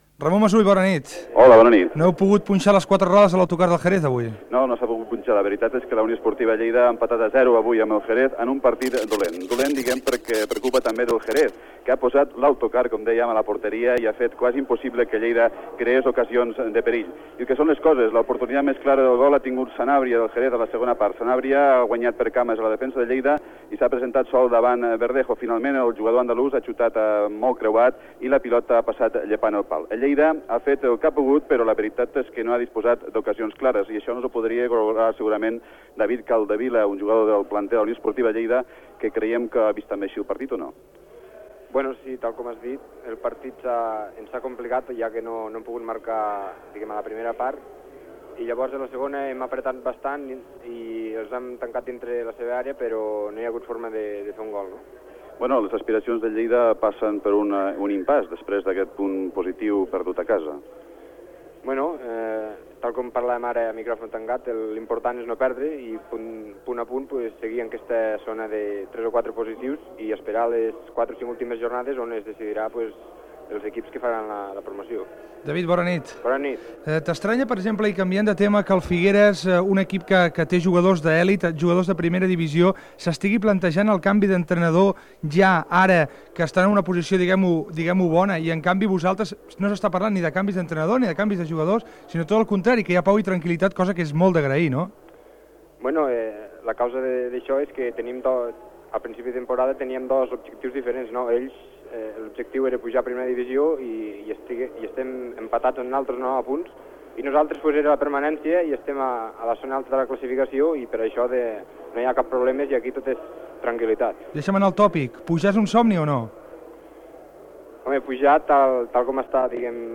Informació del partit de futbol masculí entre la Unió Esportiva Lleida i el Xerex Club Deportivo que ha acabat 0 a 0. Declaracions del jugador David Capdevila
Esportiu
FM